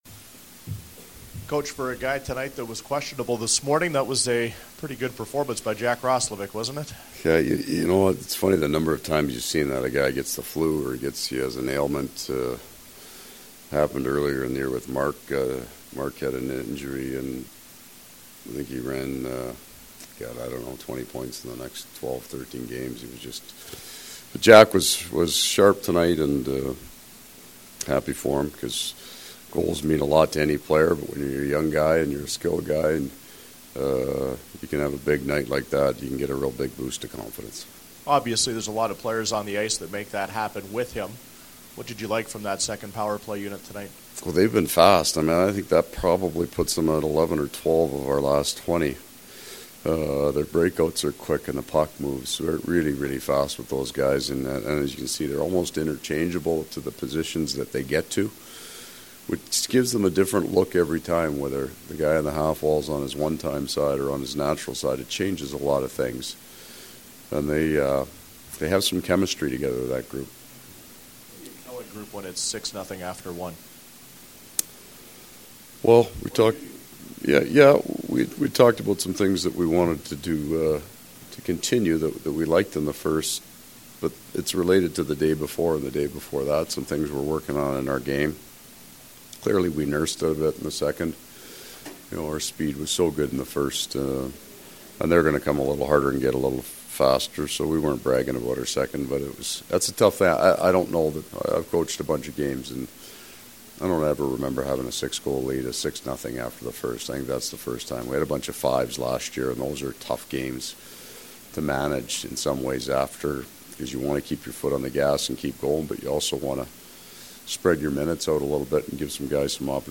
Post-game from the Jets dressing room as well as from Coach Maurice.
February-2-2019-Coach-Maurice-post-game.mp3